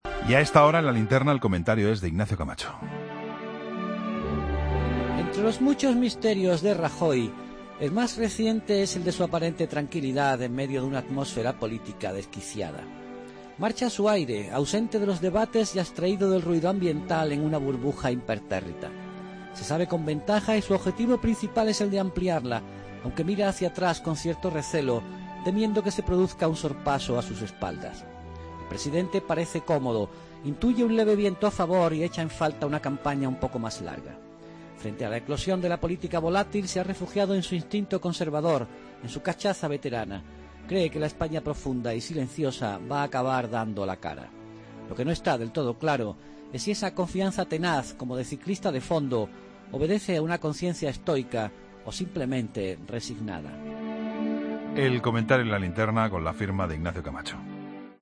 Comentario de Ignacio Camacho en La Linterna